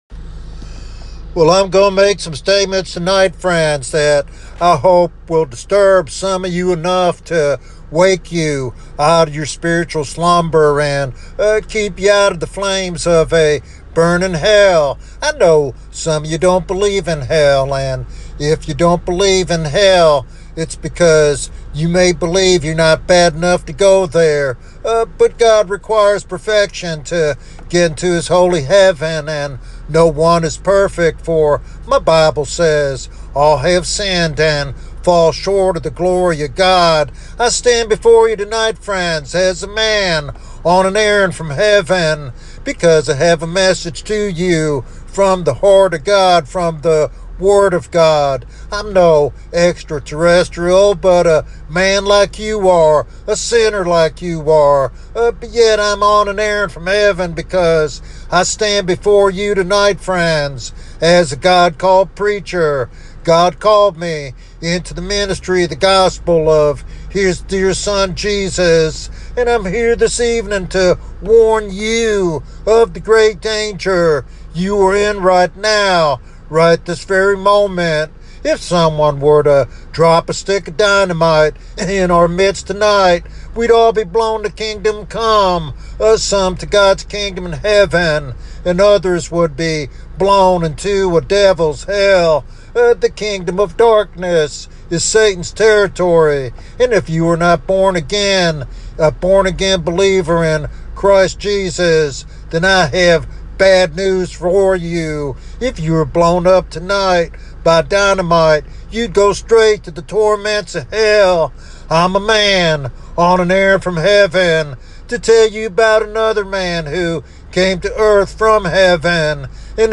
SermonIndex